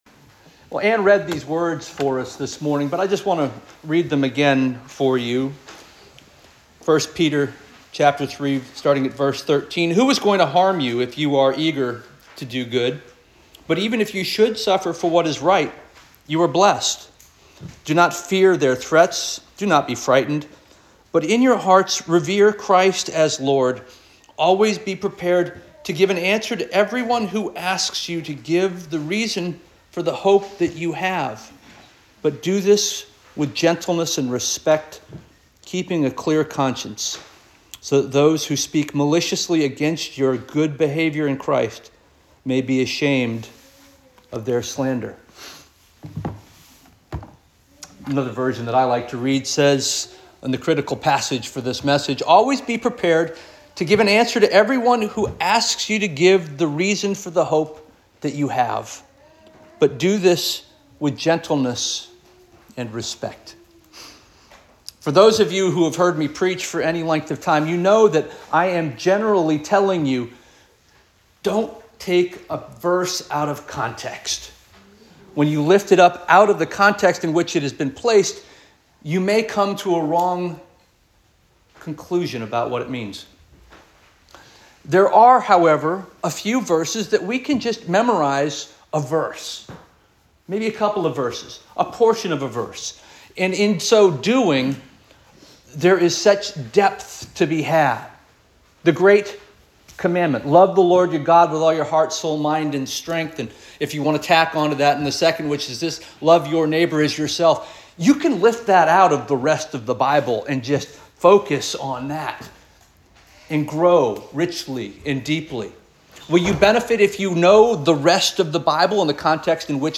March 9 2025 Sermon